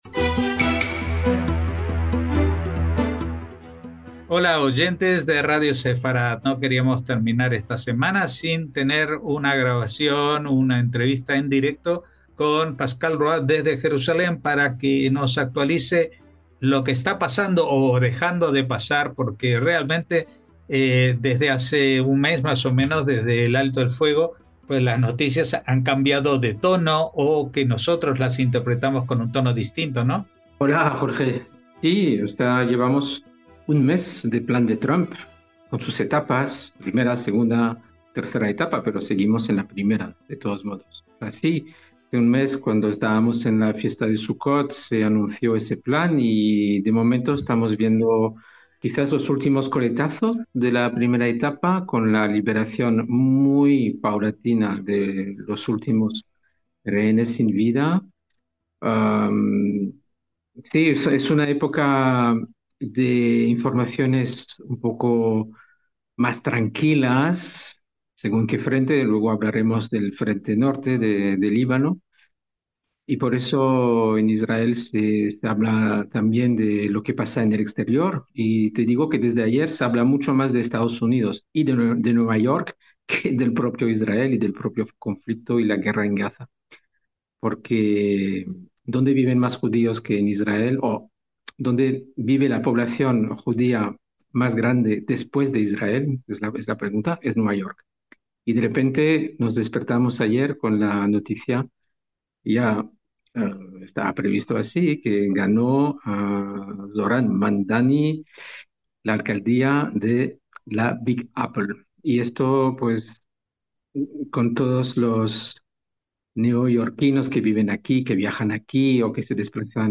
NOTICIAS CON COMENTARIO A DOS - La primera fase del alto el fuego en Gaza se va cumpliendo a regañadientes y con retrasos, pero sigue adelante.